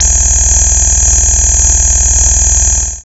56.2 SFX.wav